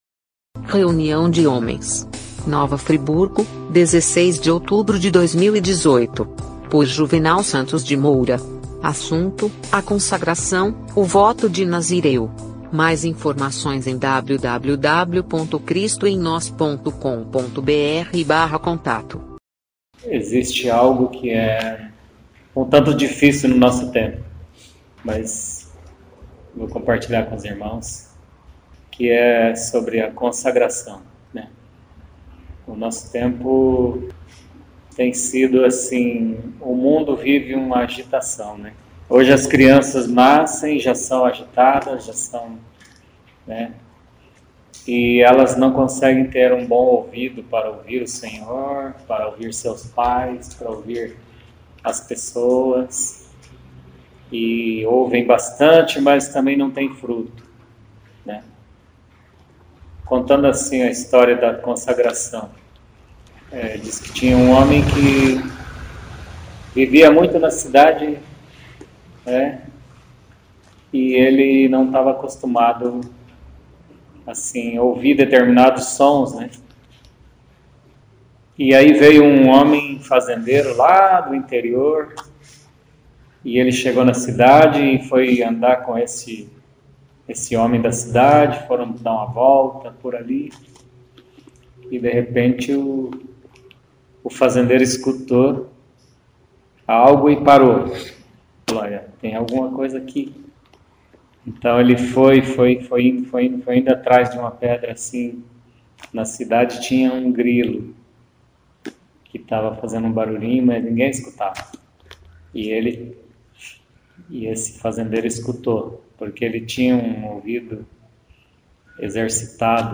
Mensagem compartilhada
na reunião de homens em Nova Friburgo